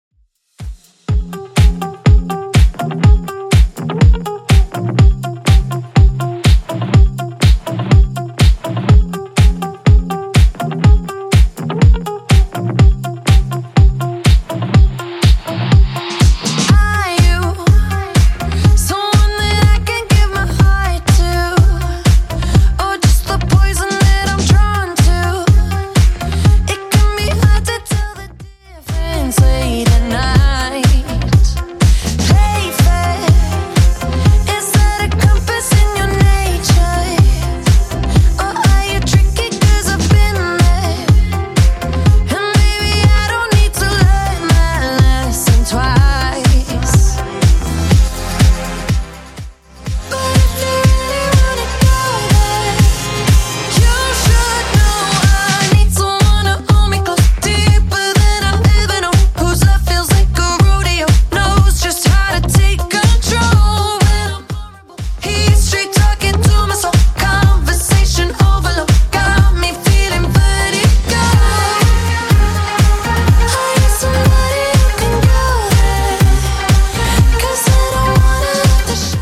BPM: 123 Time